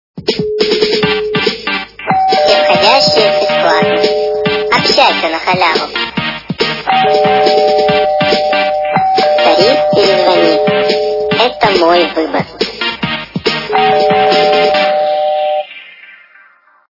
» Звуки » Люди фразы » Голос - Все входящие Бесплатно
При прослушивании Голос - Все входящие Бесплатно качество понижено и присутствуют гудки.